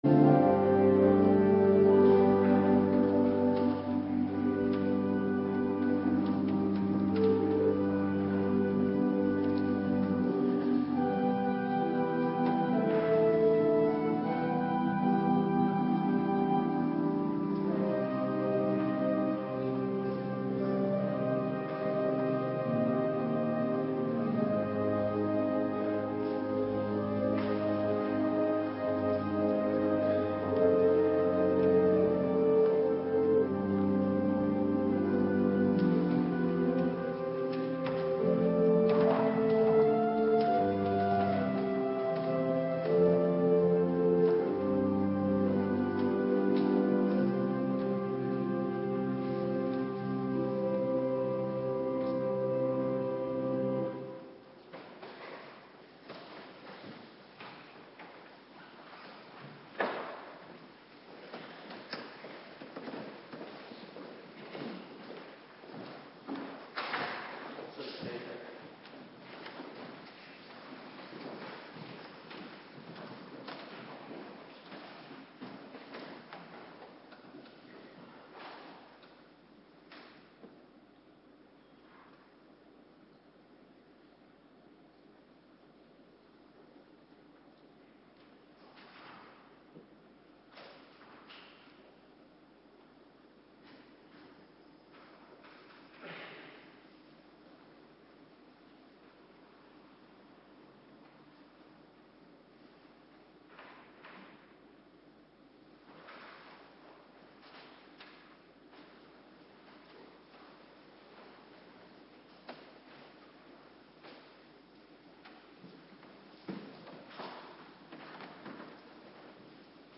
Avonddienst Voorbereiding Heilig Avondmaal - Alle wijken